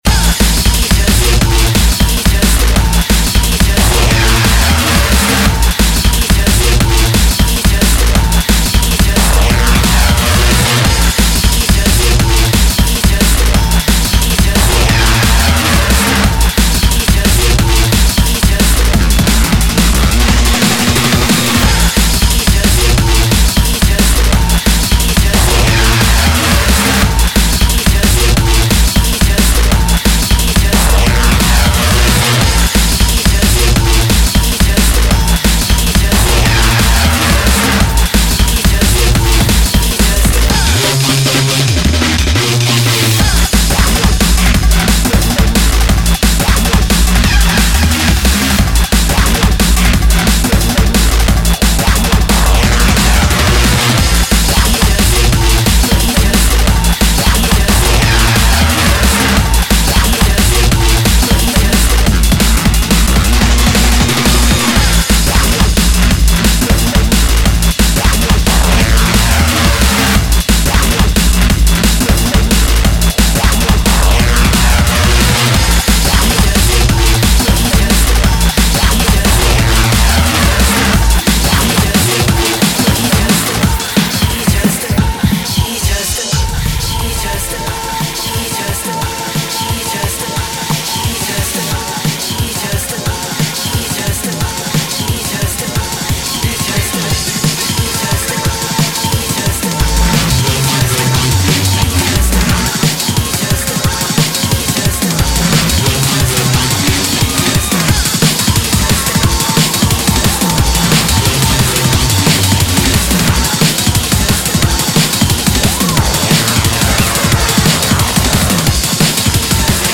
Стиль музыки: Neuro Funk